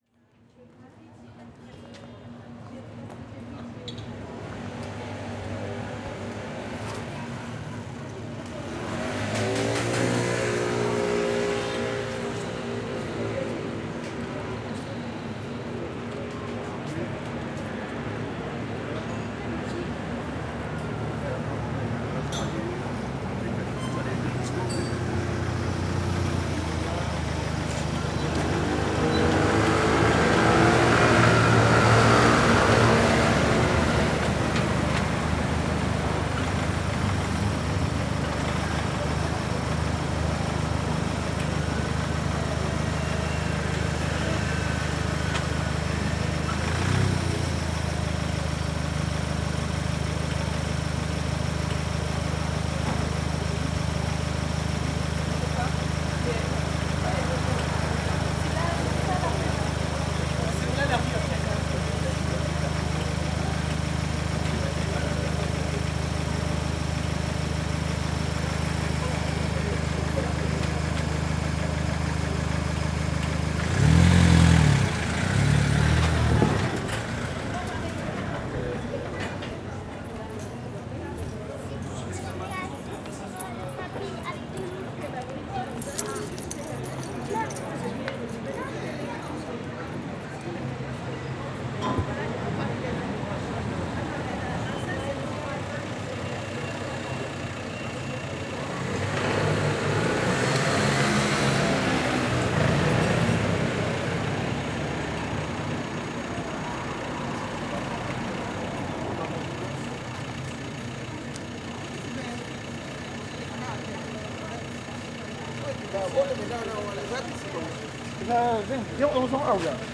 Déplacement dans la rue de Tourtille, 75020 Paris. Juillet 2020. Prise de son en extérieur : en remontant la rue de Tourtille du numéro 45 au 51, avec le relais de Belleville , en fin de prise de son.
fr CAPTATION SONORE
fr Scène de rue
fr Voiture
fr Conversation